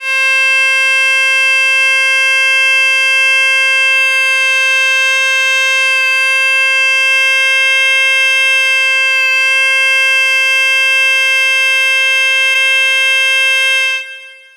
Punteiro de gaita gallega en escala diatónica tocando la nota C
gaita